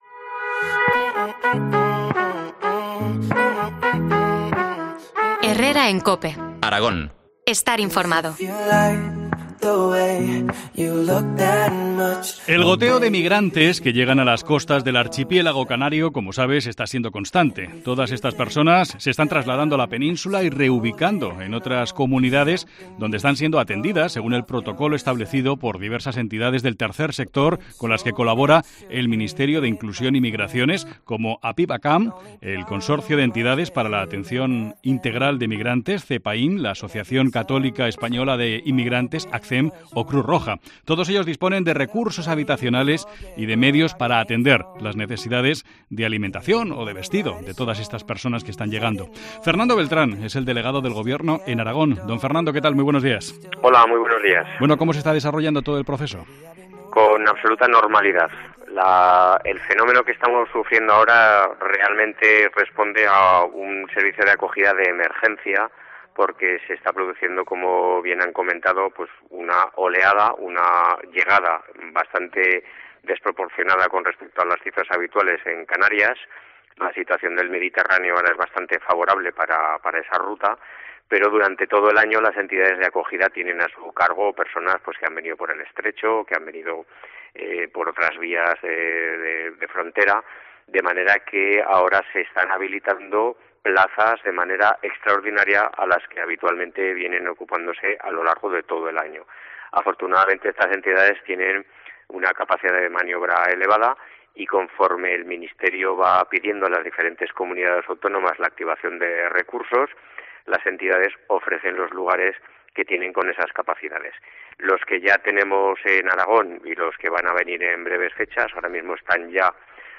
Entrevista al Delegado del Gobierno en Aragón, Fernando Beltrán, sobre la llegada de migrantes.